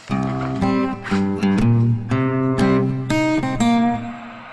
Звук перехода между кадрами
гитарный вариант